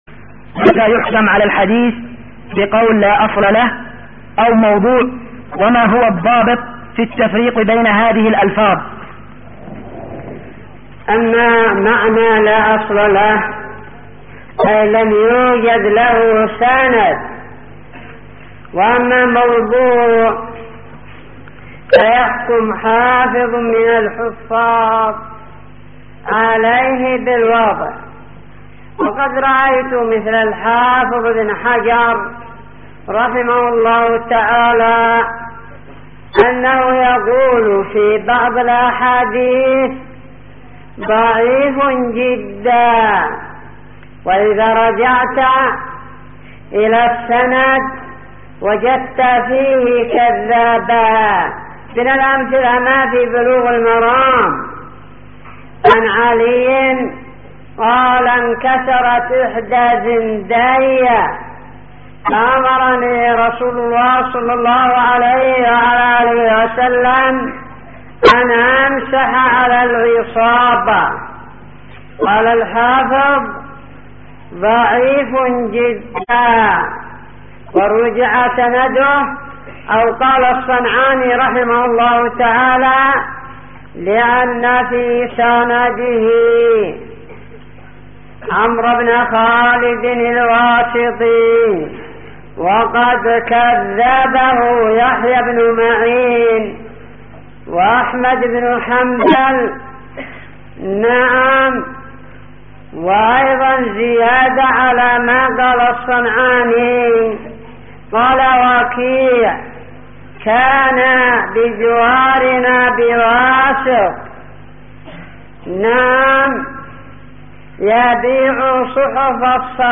------------ من شريط : ( أسئلة مسجد السلام بعدن )